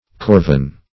Corven \Cor"ven\ (k?r"ven)